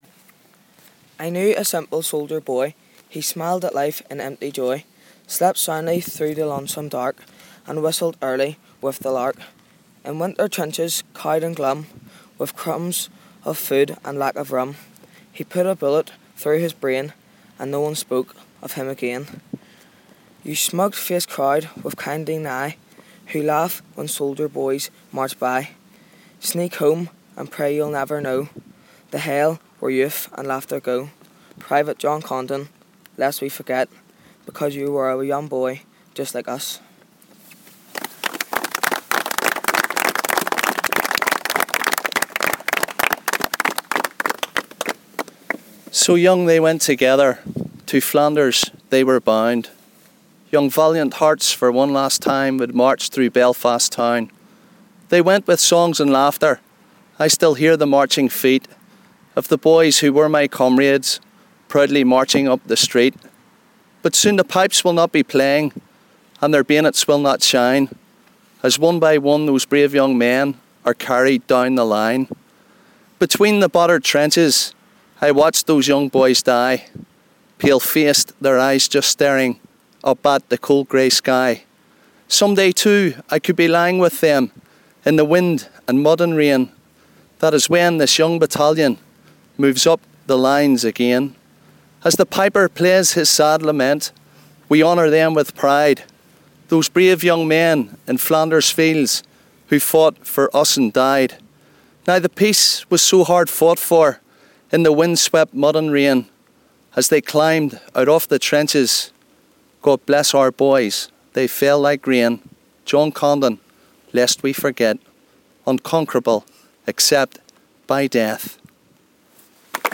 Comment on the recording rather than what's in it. Poems at John Condon graveside